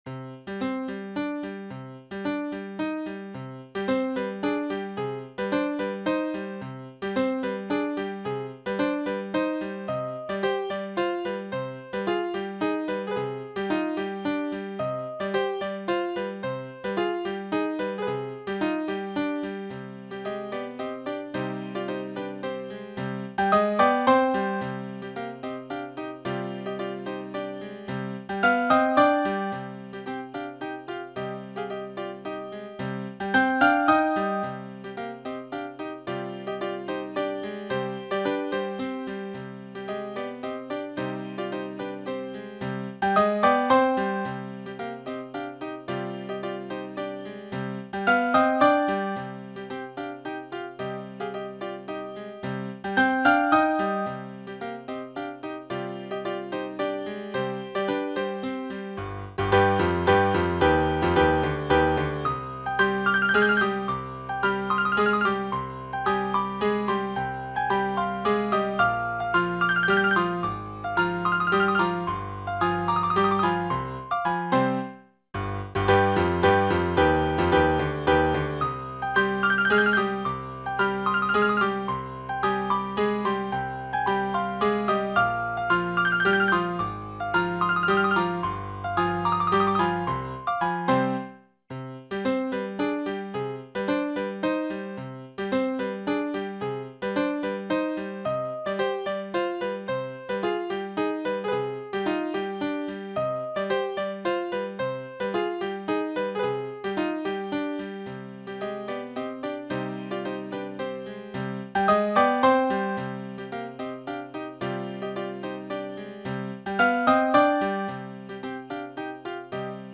Ալեքսանդր Սպենդիարյան – Էնզելի (Նոտաներ Դաշնամուրի համար) / Alexander Spendiaryan – Enzeli (Partitions Piano)
Enzeli-Partition-Piano-Audio.mp3